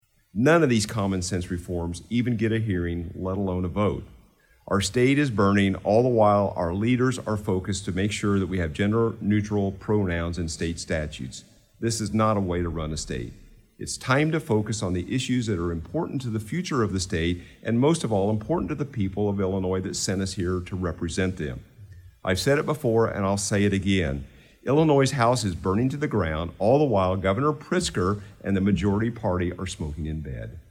Shelbyville, IL-(Effingham Radio)- State Representative Brad Halbrook (R-Shelbyville) said today in a capitol press conference that one way the majority party is making the state of Illinois worse is through excessive spending.